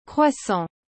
Em francês, a pronúncia correta é /kʁwa.sɑ̃/.
Como se pronúncia croissant?
A pronúncia correta é “krwa-sã” (croissant).